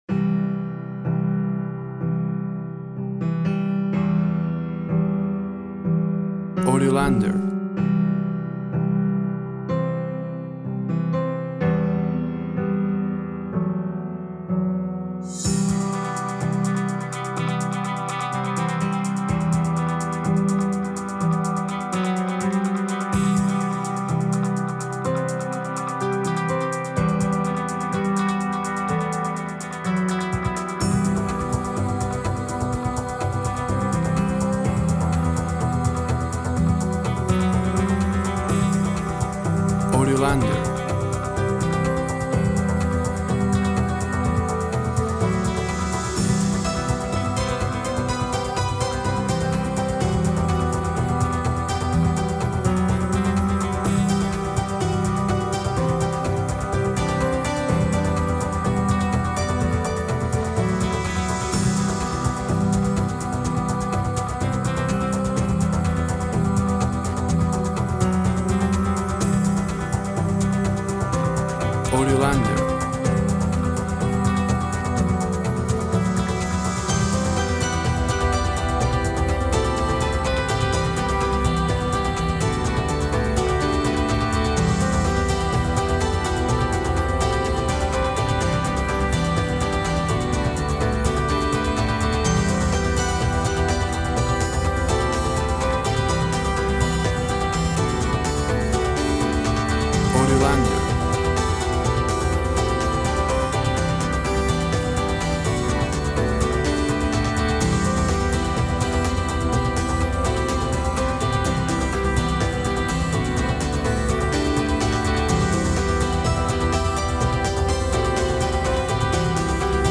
Ambience relaxed music.
Tempo (BPM) 180